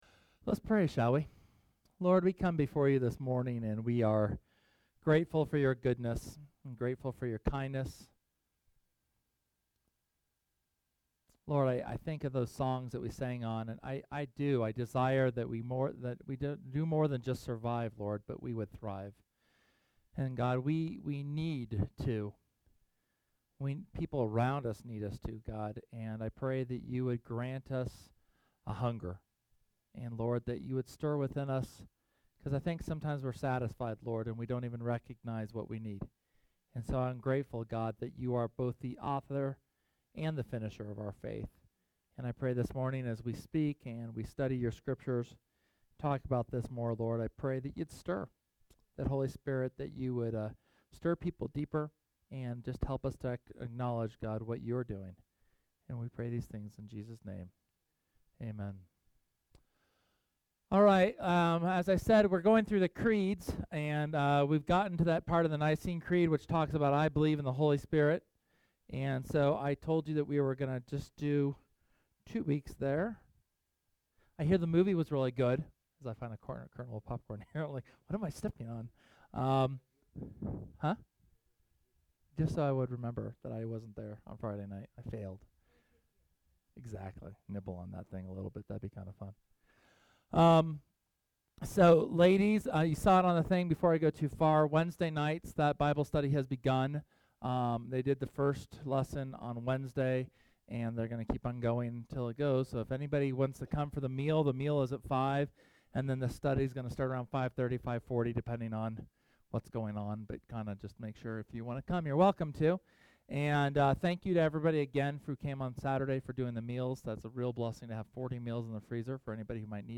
The eighth sermon in our series on the Christian Creeds (Apostles, Nicene and Athanasian Creeds). These creeds help us in our understanding of the fundamentals of the faith so that we may be better equipped to minister to others.